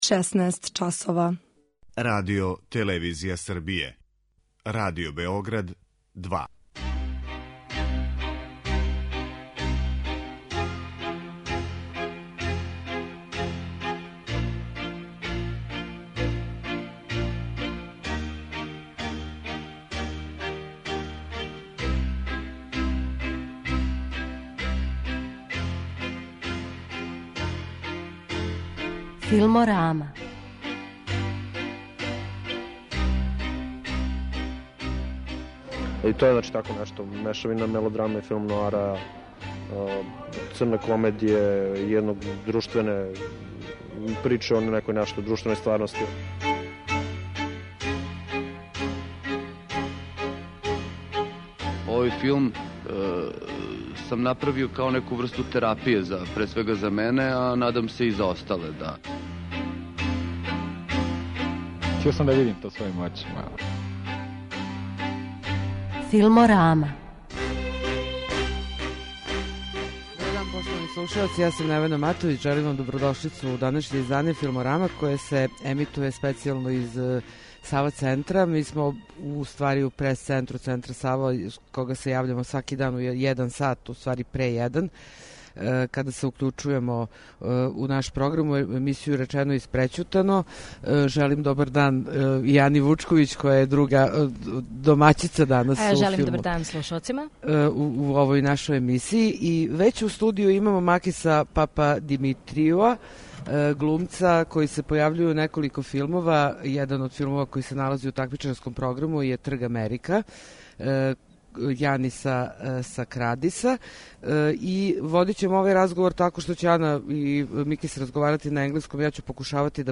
Специјално издање 'Филмораме' емитује се из Центра 'Сава', где је у току 46. ФЕСТ.